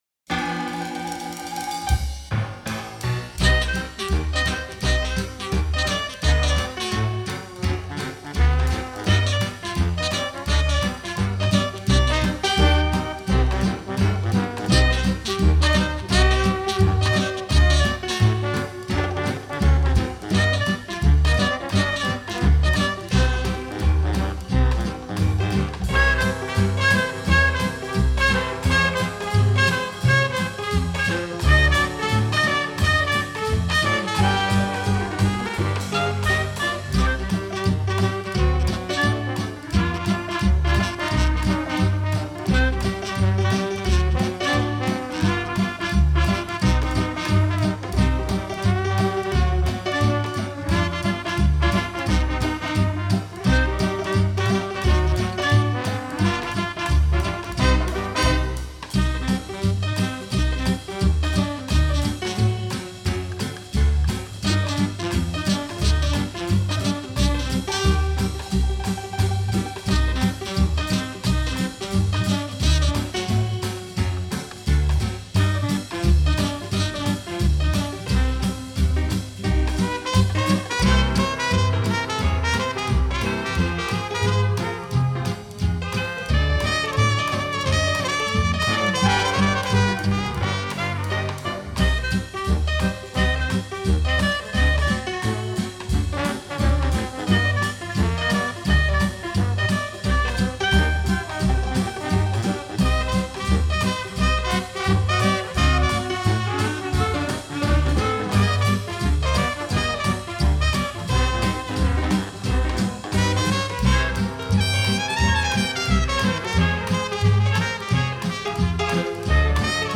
soundtrack
джаз